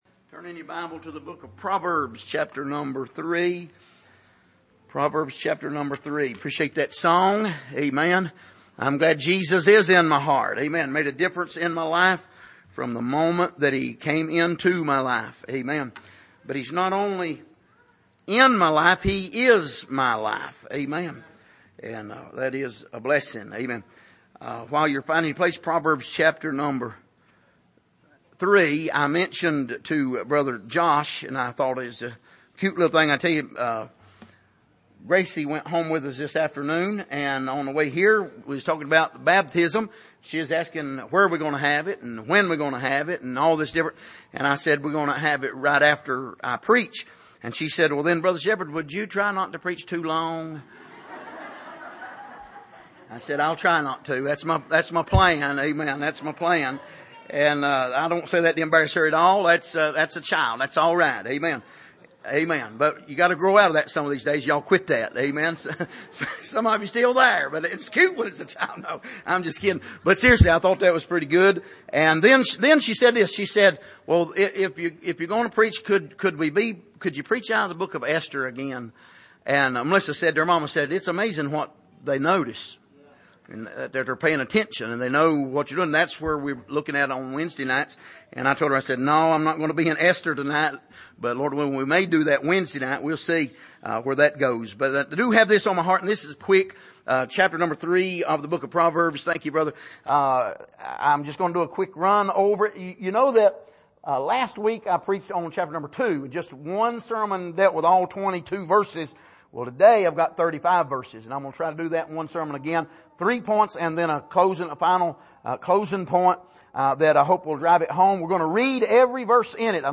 Passage: Proverbs 3:1-2 Service: Sunday Evening